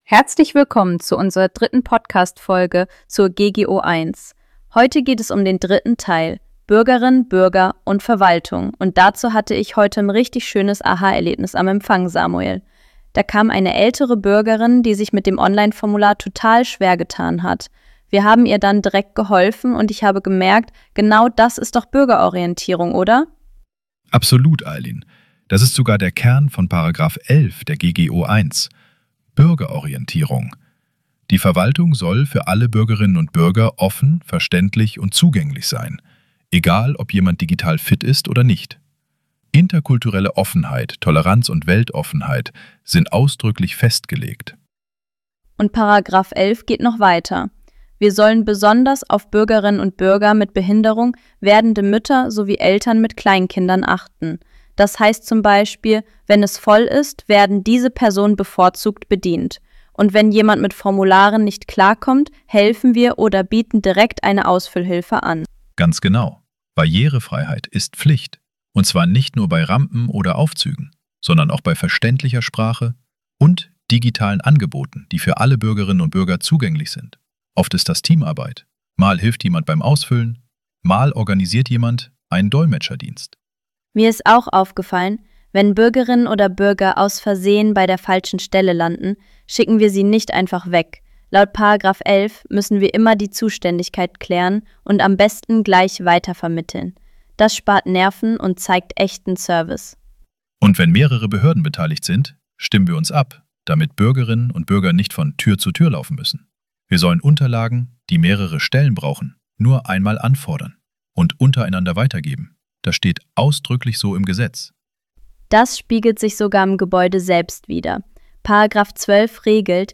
Die Erstellung dieser Podcastreihe erfolgte mit Unterstützung von Künstlicher Intelligenz.